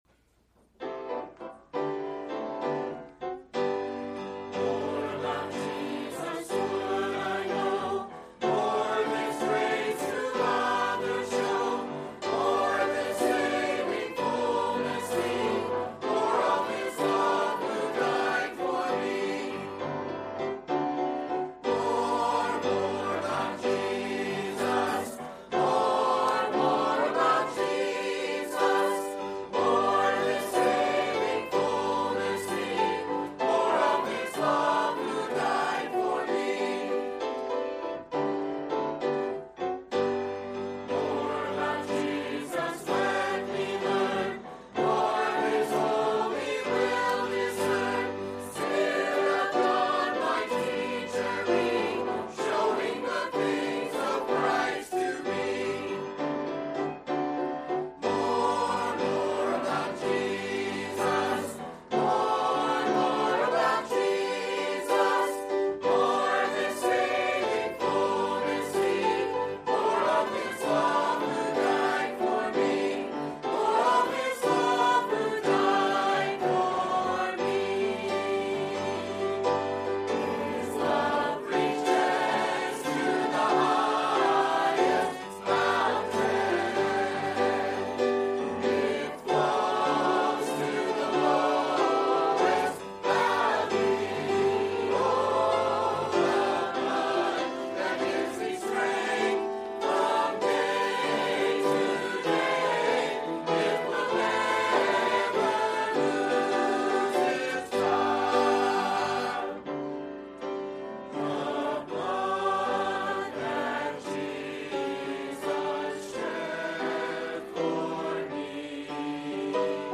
A message from the series "Focused on the Family."